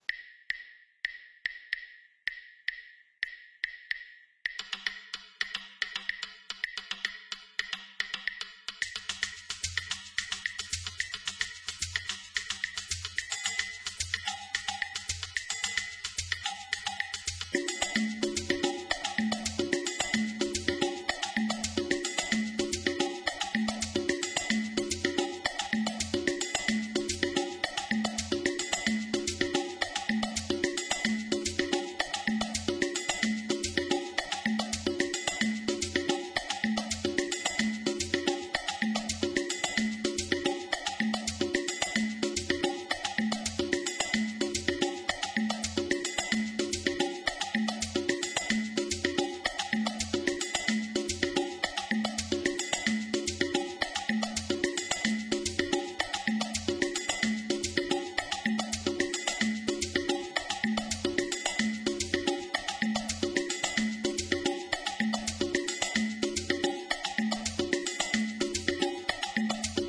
This groove is based on the guaguanco.